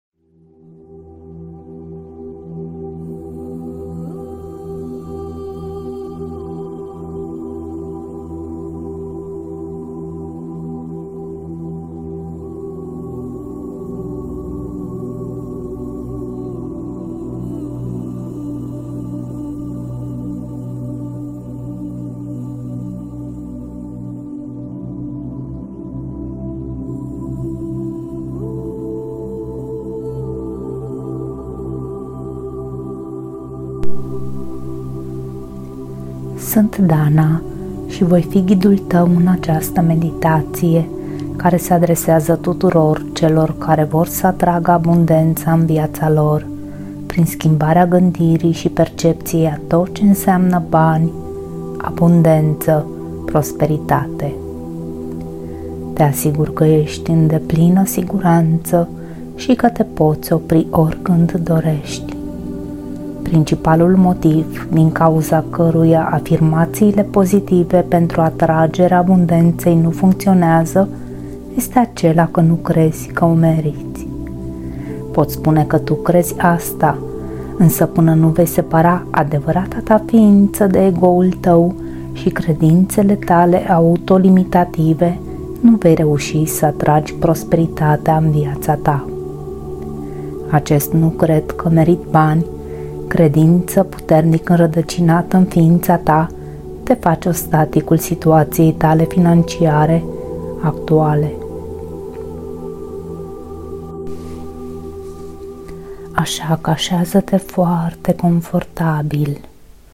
Această meditație ghidată este concepută pentru a-ți întări încrederea în sine, a-ți clarifica scopurile și a te reconecta cu puterea ta interioară. Prin tehnici de relaxare profundă și vizualizare pozitivă, vei învăța să îți consolidezi un ego sănătos și echilibrat, care te susține în depășirea provocărilor și în manifestarea potențialului tău maxim.
Acest program este o combinație perfectă de afirmații pozitive, sunete binaurale și frecvențe terapeutice, toate concepute pentru a-ți stimula subconștientul și a-ți consolida gândirea orientată spre abundență.